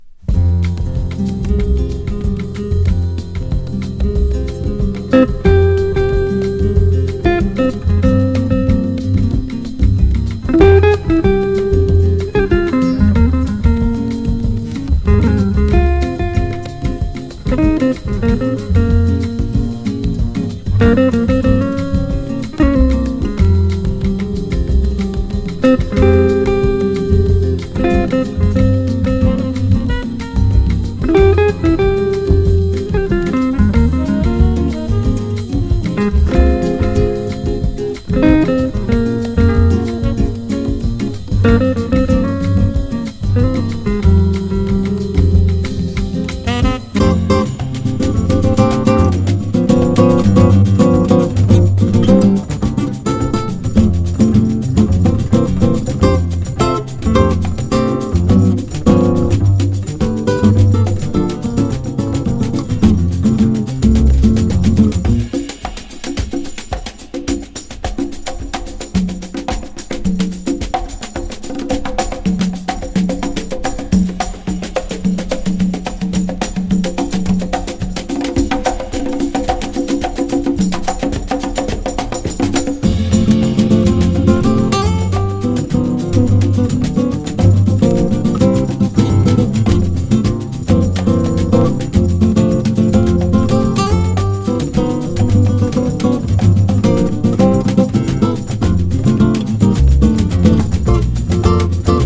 ボッサ・ジャズ・アルバム。アダルト・メロウ
サンバ・ジャズ